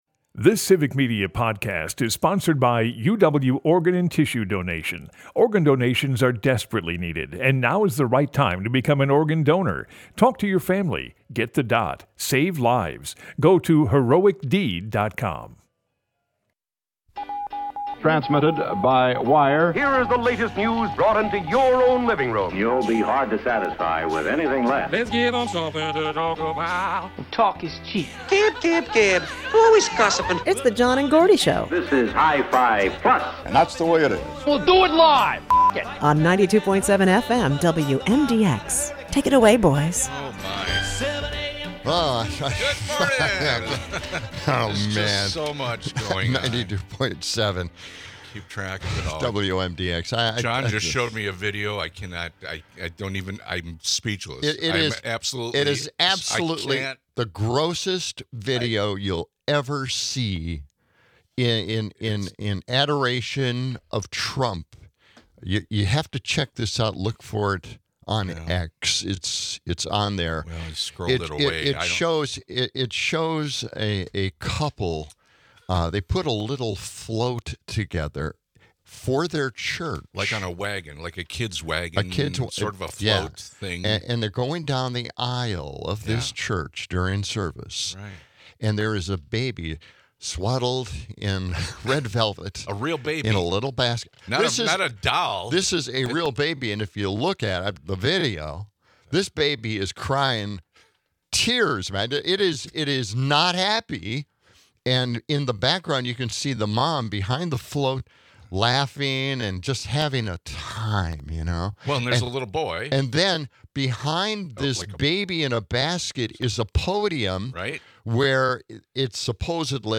Broadcasts live 6 - 8am weekdays in Madison.
Later, the conversation continues on separation of church and state in schools, then turns to Trump's cabinet picks, and how he's using it as a tactic to maintain headline dominance in the media. Closing out, we hear Jeff Daniels take on current politics, the day after the election.